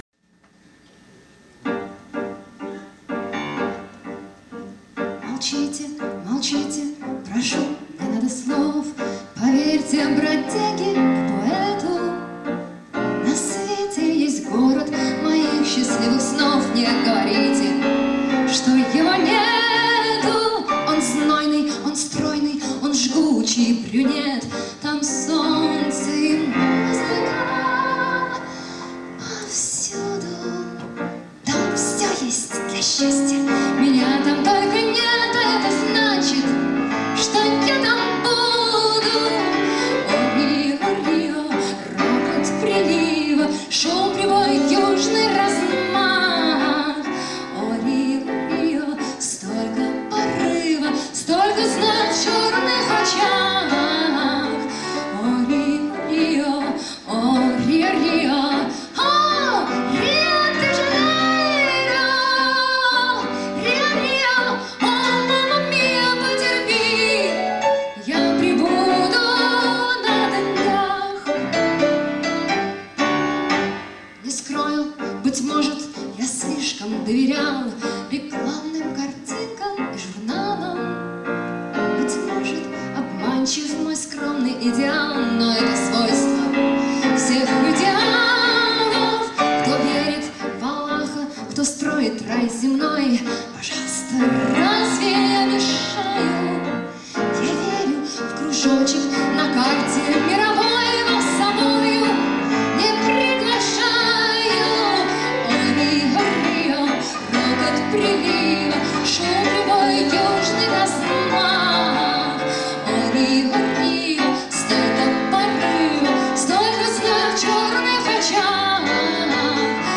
скачать mp3 (Концертная запись "с воздуха")